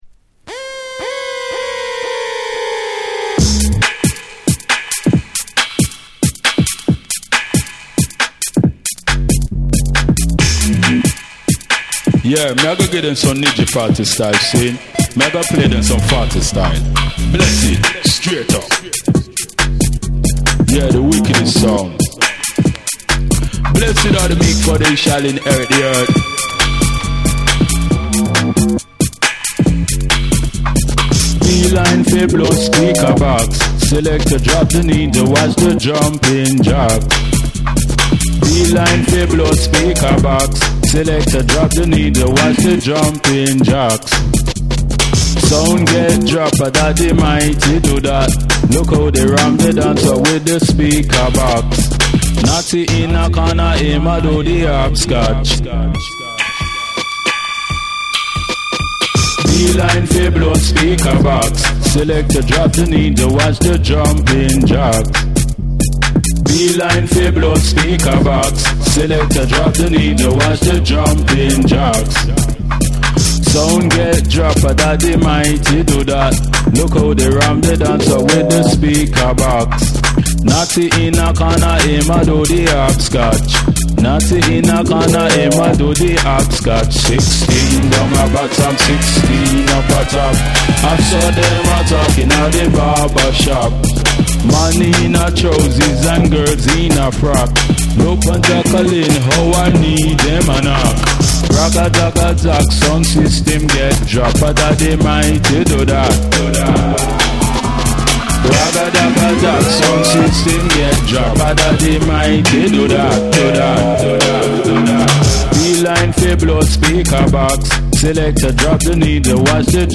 BREAKBEATS / REGGAE & DUB